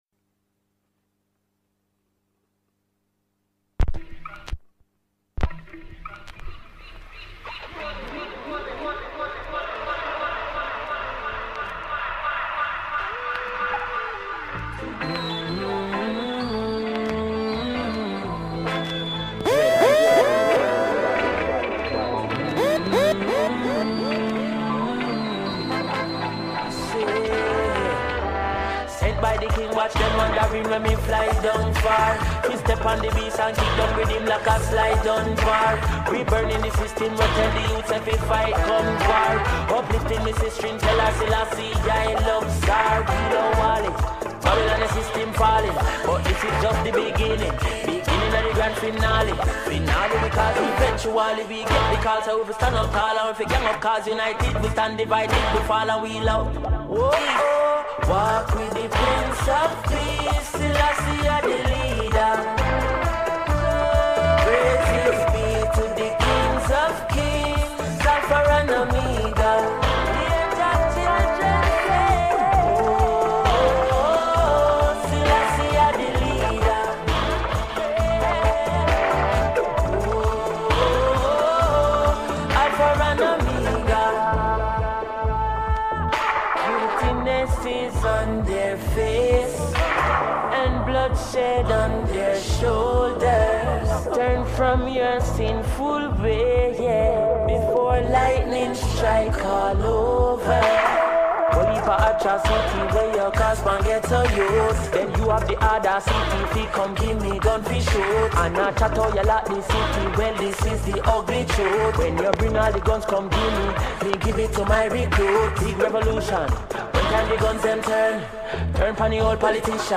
weekly radio sesh